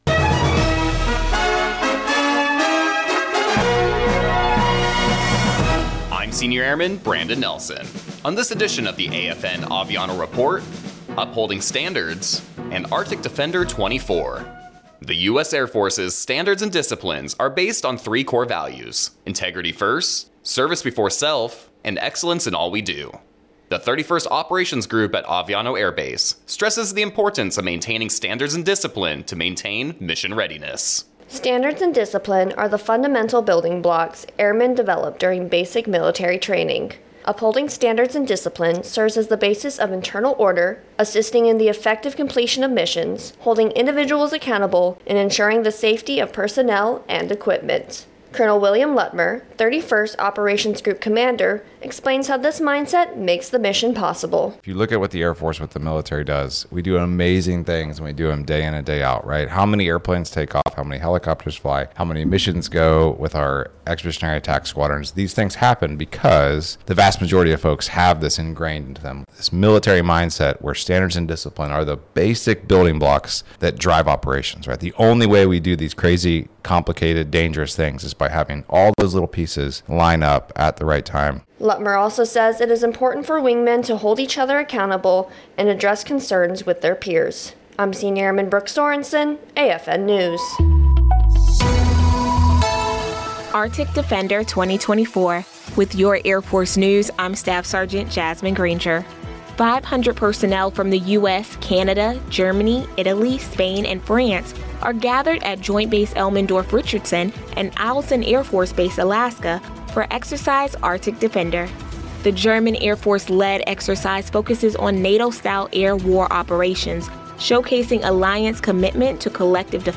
American Forces Network (AFN) Aviano radio news reports on the 31st Operations Group at Aviano Air Base stressing the importance of maintaining standards and discipline to ensure mission readiness.